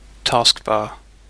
Ääntäminen
Ääntäminen UK Haettu sana löytyi näillä lähdekielillä: englanti Käännös Konteksti Substantiivit 1.